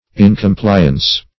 incompliance - definition of incompliance - synonyms, pronunciation, spelling from Free Dictionary
Incompliance \In`com*pli"ance\, n.